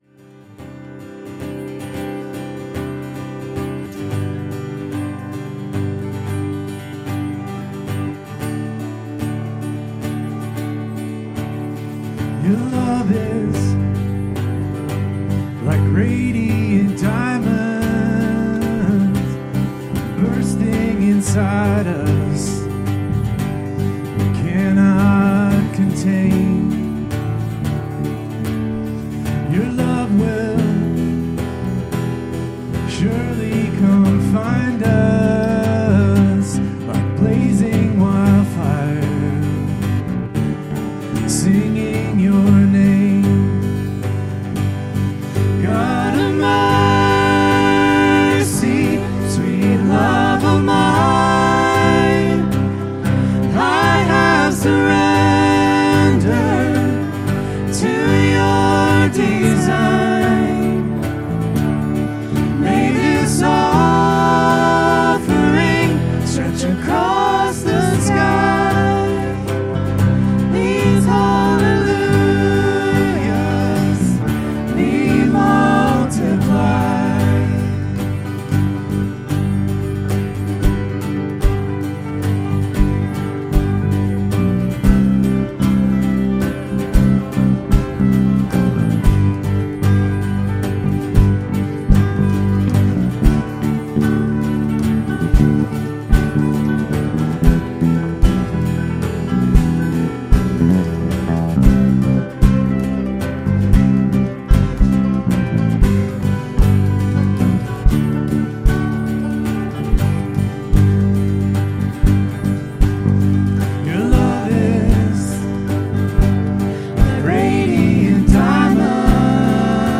Worship 2026-02-15